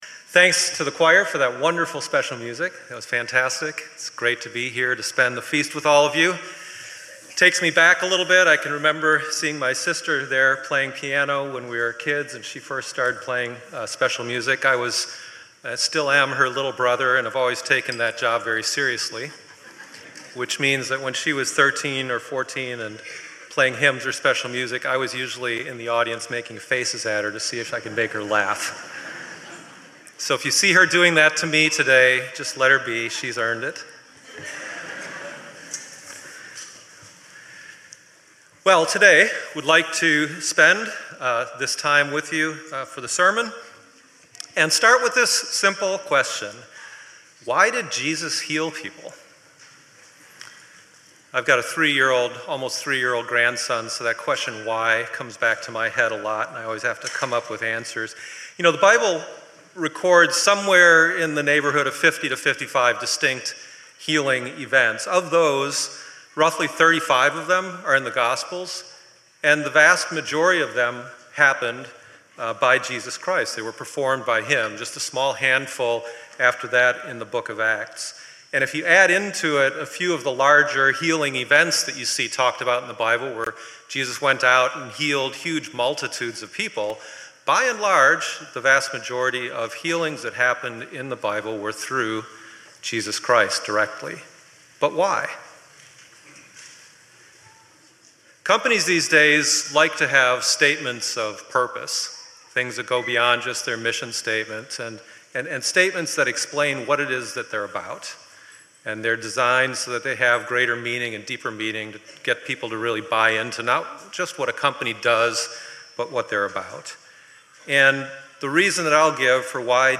Well, today, I would like to spend this time with you for the sermon and start with this simple question.